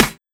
SNARE43.wav